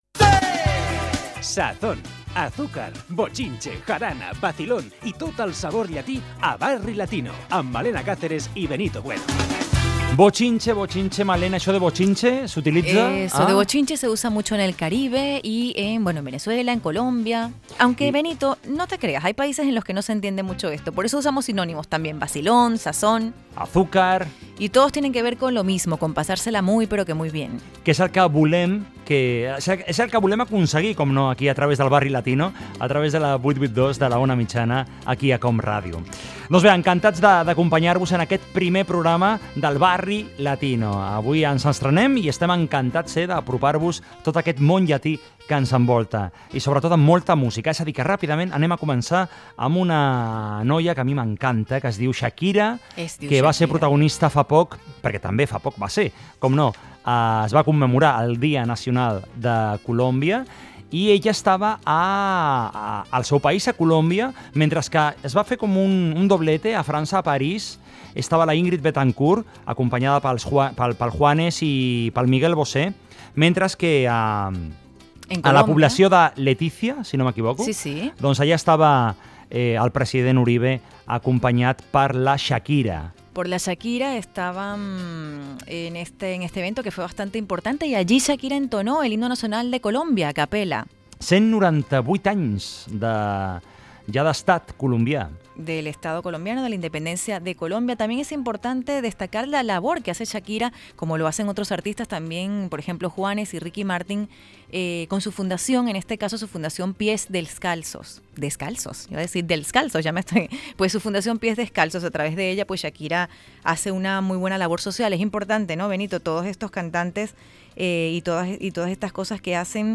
Careta i inici del primer programa. Comentari sobre la paraula "bochinche" i la cantant Shakira
Musical